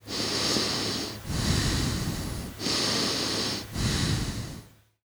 瞎子房间癞子呼吸.wav